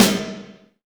SPLATE SNA29.wav